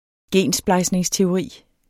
Udtale [ ˈgεnˌsbɑjˀleŋs- ]